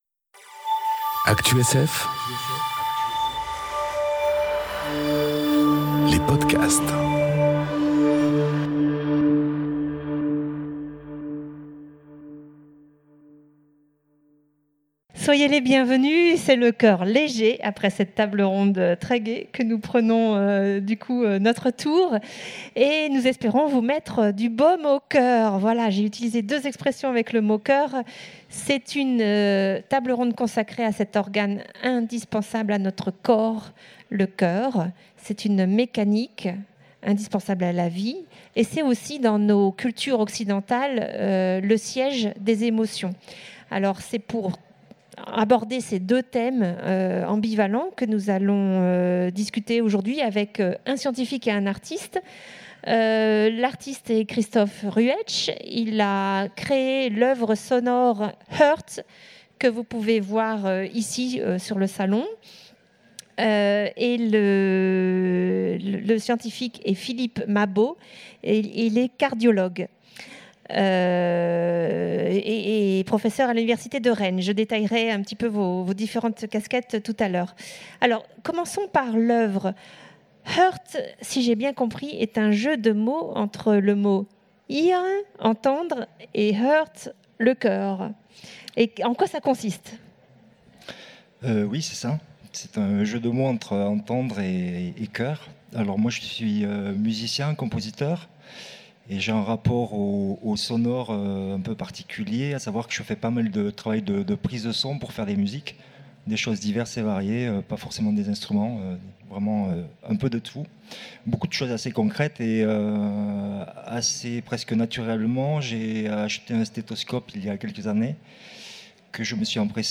Conférence L’empreinte du cœur enregistrée aux Utopiales 2018